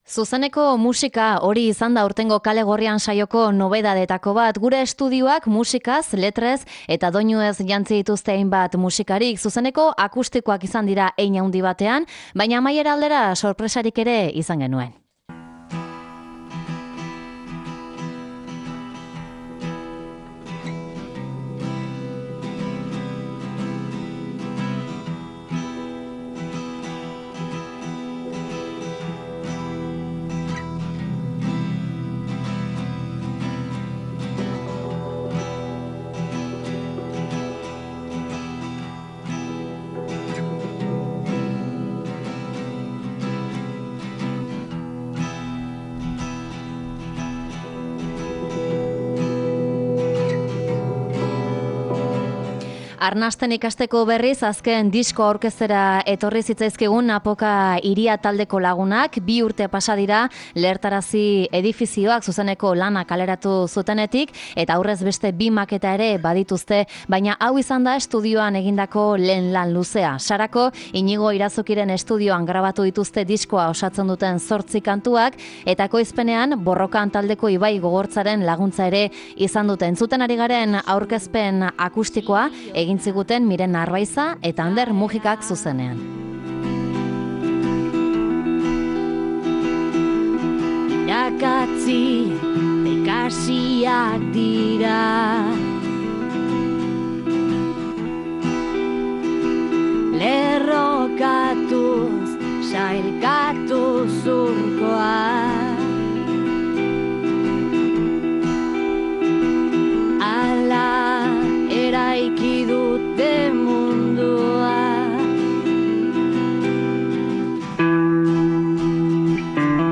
Musikari aunitz izan dira gurera etorri direnak. Zuzeneko kantu artean musikariak hurbilagotik ezagutzeko aukera izan dugu.